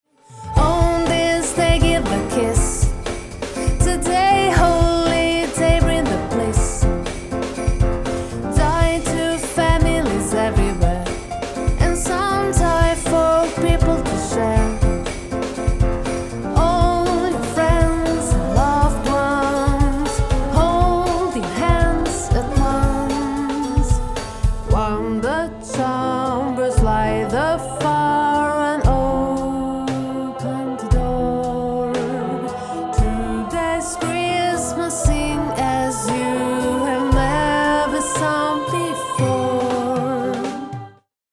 Mentre componevo la canzone, nella mia “temp track” vocale ho sempre cantato everywhere come una terzina discendente.
Puoi ascoltare l’effetto qui (anche se cantare non è il mio mestiere):
Give_a_Kiss_temp_vocal_track.mp3